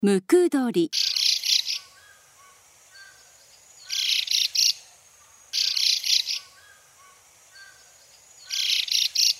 ムクドリ
【鳴き声】普段は「ジュ」「ギュル」「ギーッ」などと鳴き、集団で鳴き交わすと騒がしくなる。繁殖期には、オスは地鳴きを組み合わせた複雑な声でさえずる。
ムクドリの鳴き声（音楽：156KB）
mukudori2.mp3